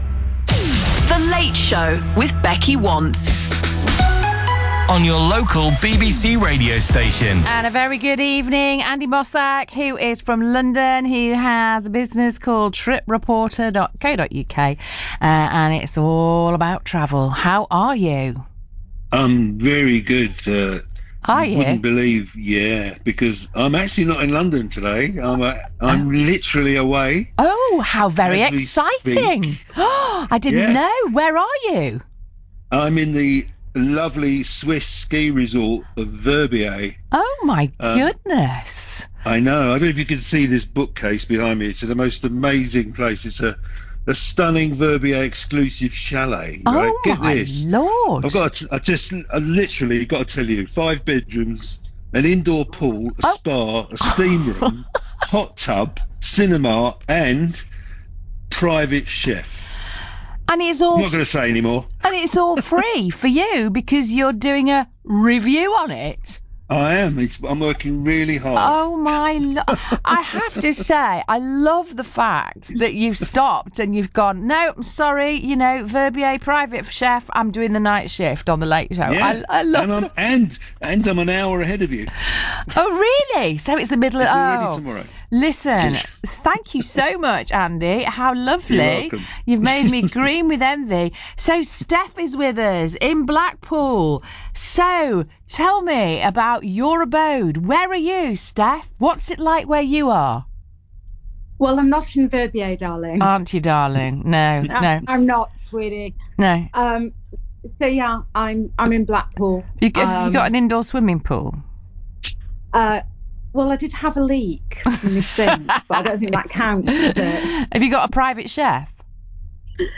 Live broadcasts from around the globe.